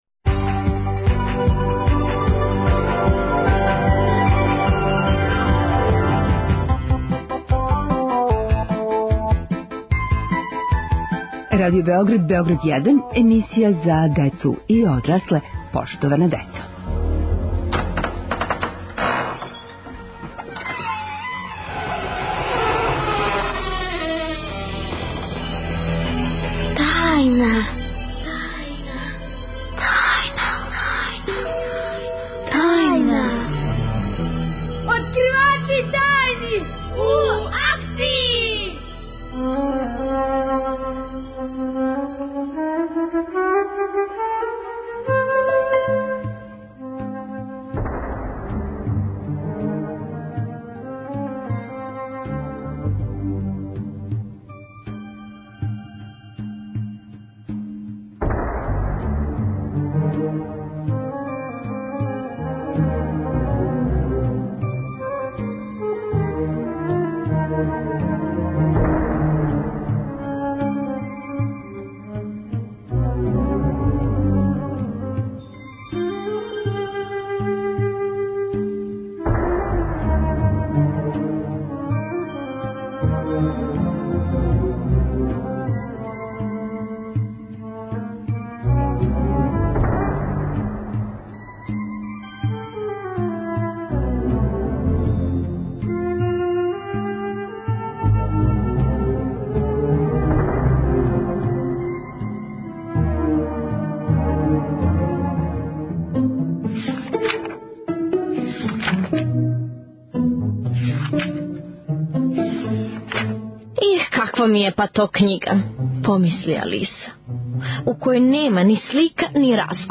Откривамо тајне бајки. Гости: деца и писци.